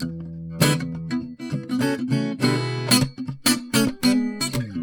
Para entender un poco de que estamos hablando, escucharemos un sonido grabado sin procesar.
guitarra.mp3